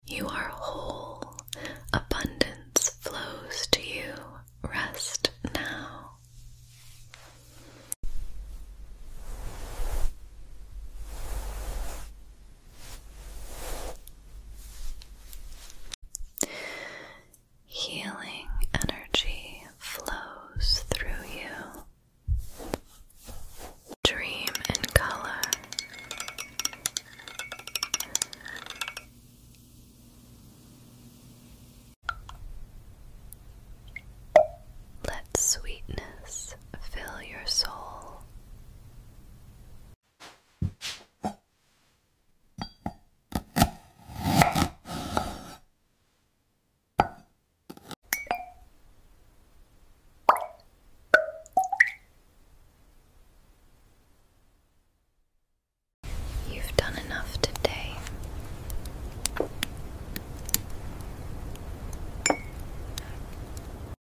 Upload By PREMIUM AI ASMR